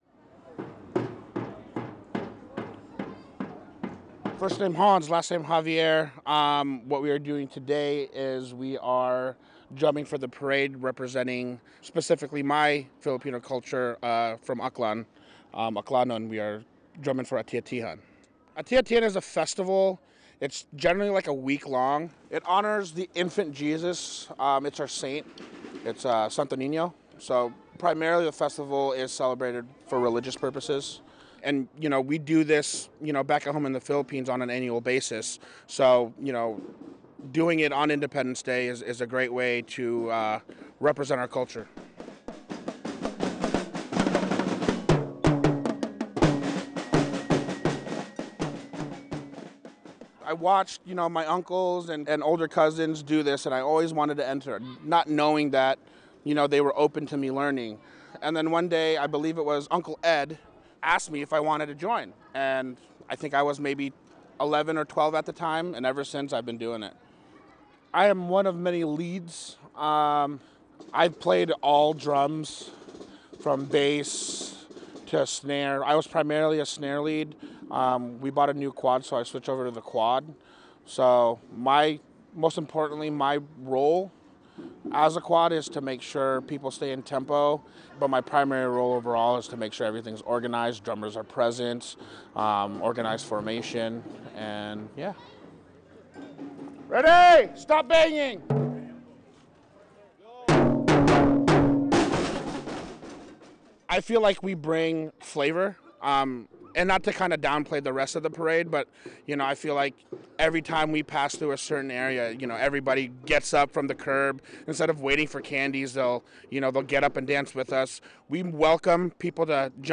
Every year on July 4th, drums awaken the streets of downtown Juneau. This iconic sound is Juneau Ati-Atihan, a musical marching group that brings the Filipino festival of the same name from Aklan to Juneau.